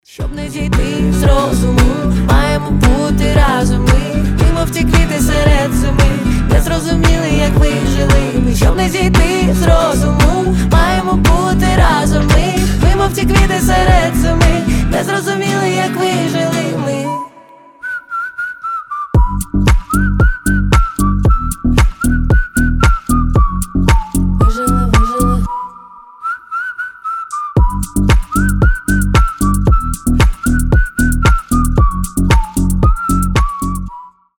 • Качество: 320, Stereo
свист